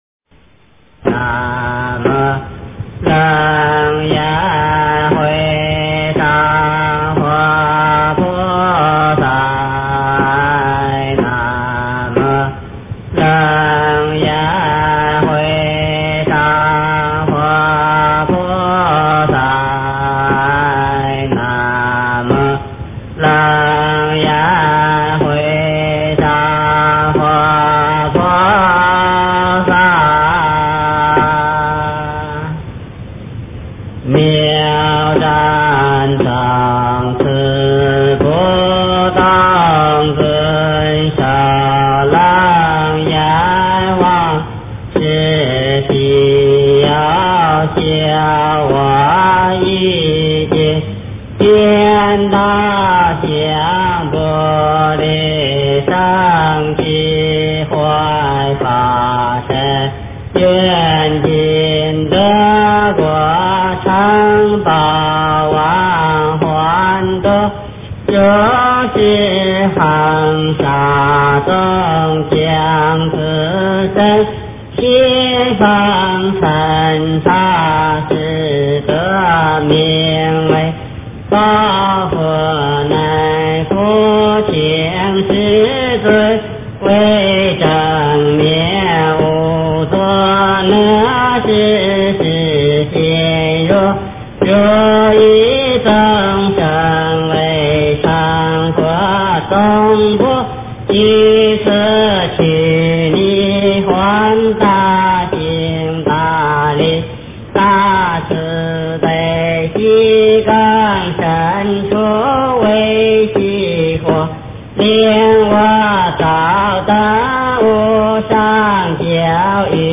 经忏
佛音 经忏 佛教音乐 返回列表 上一篇： 南无阿弥陀佛--般若心曲版 下一篇： 菩萨般若波罗蜜--佚名 相关文章 般若菠萝蜜颂--佚名 般若菠萝蜜颂--佚名...